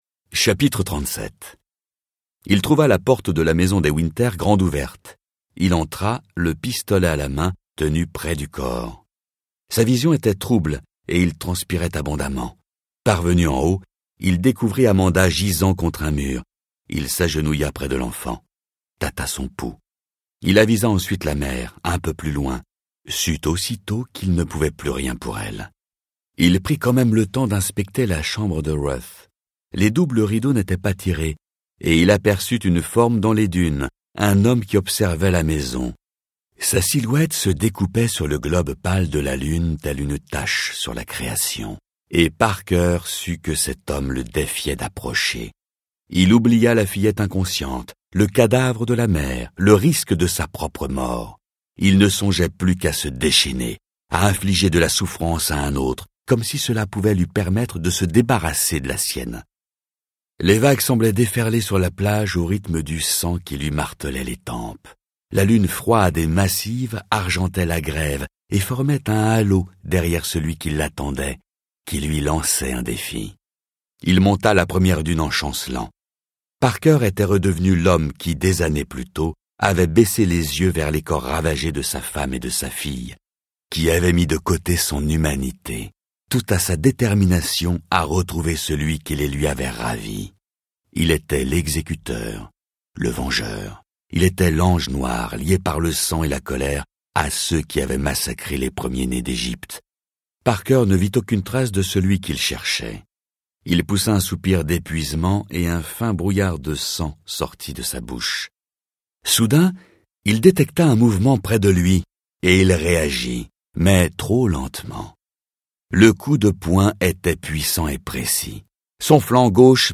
Tous mes livres audios
John Connolly "Le chant des dunes" Extrait 2 Toutes les voix